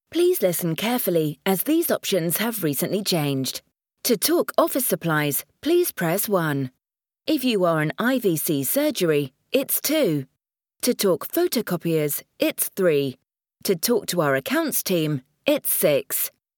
I can arrange voice over recordings for music on hold and IVR projects for your business.
I only use industry standard voice artists for my voice overs to create a professional finished product.
IVR Demo 2